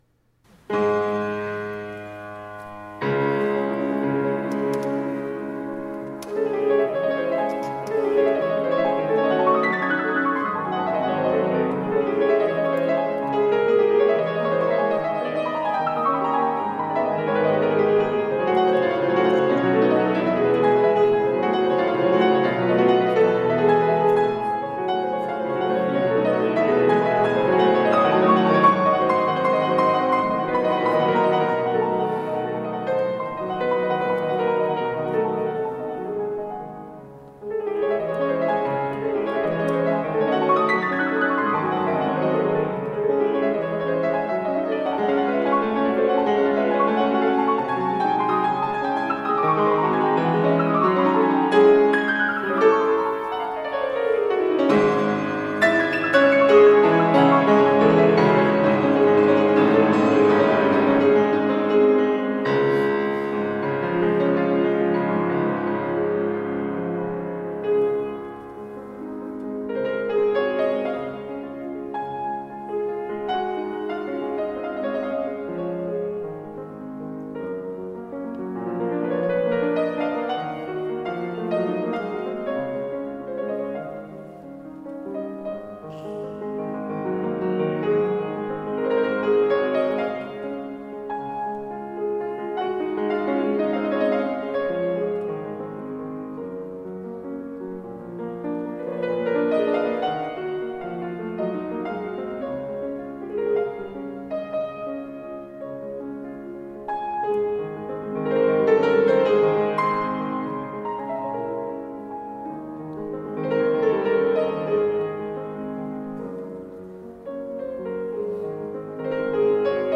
Pianistin